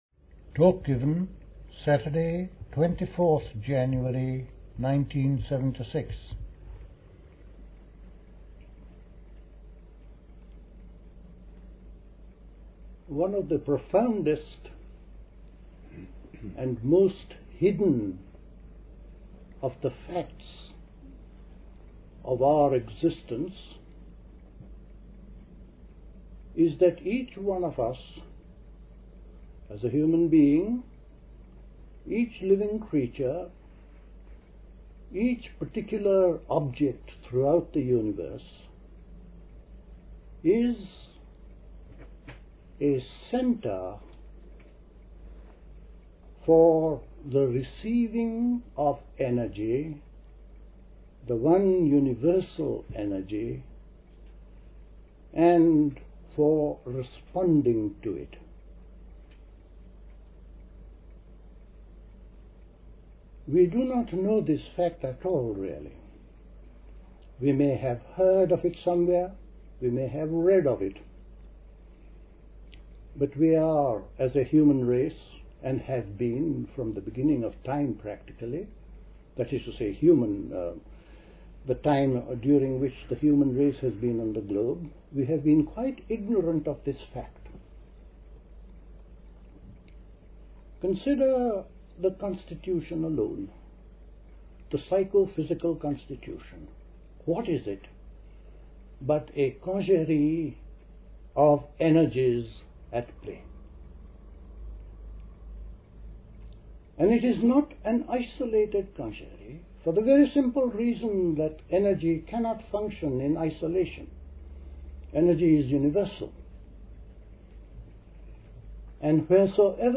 A talk
at Dilkusha, Forest Hill, London on 24th January 1976